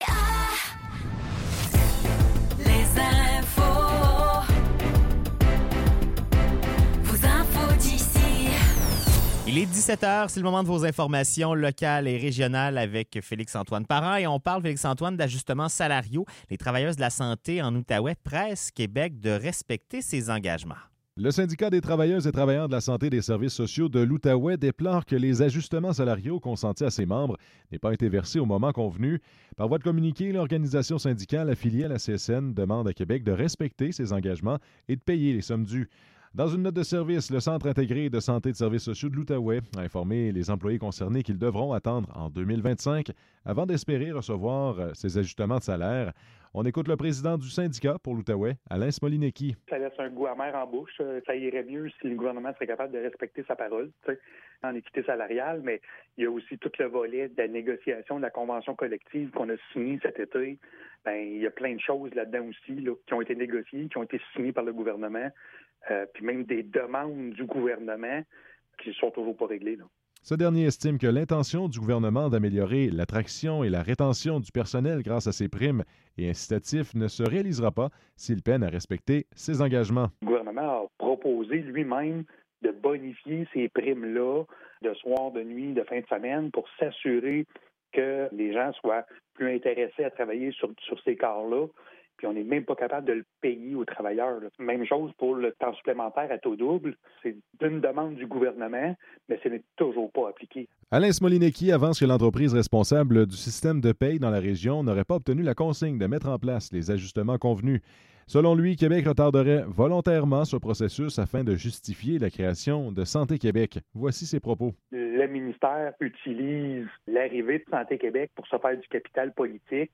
Nouvelles locales - 4 novembre 2024 - 17 h